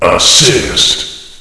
flak_m/sounds/announcer/int/assist.ogg at 098bc1613e970468fc792e3520a46848f7adde96